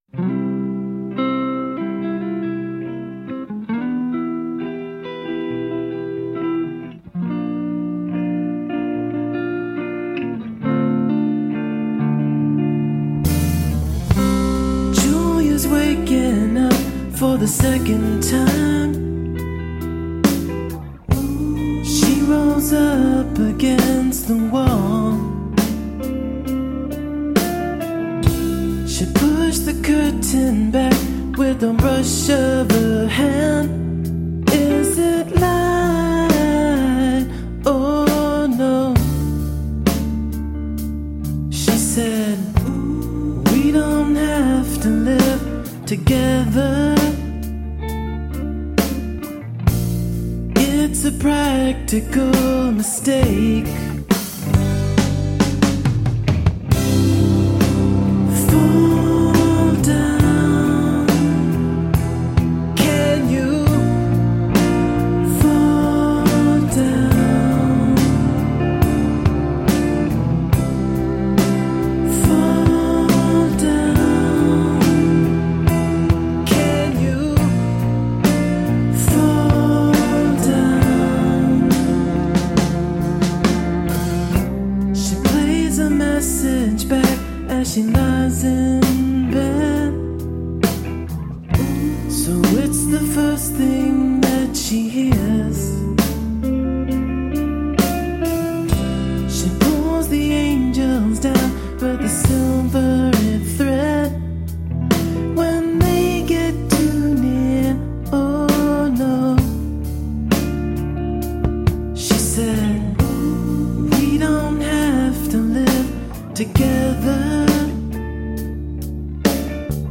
A catchy brand of alternative roots rock.
Tagged as: Alt Rock, Folk-Rock, Folk